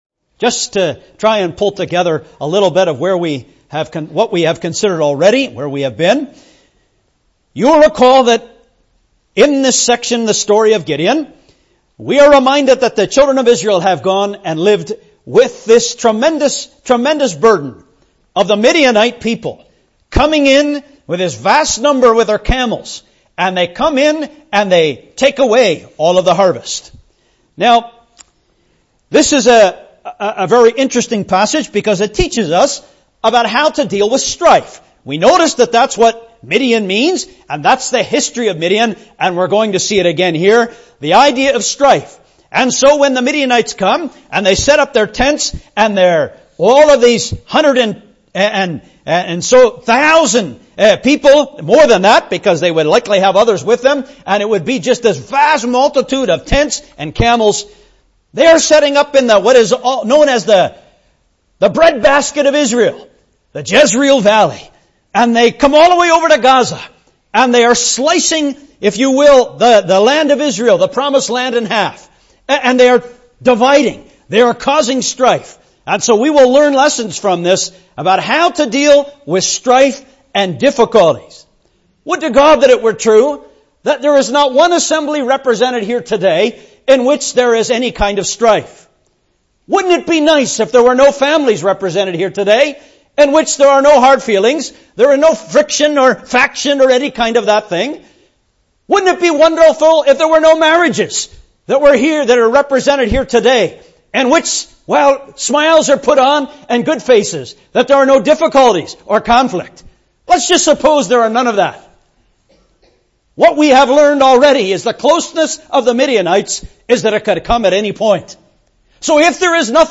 Listen as insights are given into the spiritual truths God is teaching us through the divinely guided reduction of Gideon’s army from 32,000 to 300 soldiers. The smallness, emptiness and brokenness of the weapons with which Gideon’s army overcame the enemy are challenging issues for us to face. (Preached: 29th October 2016)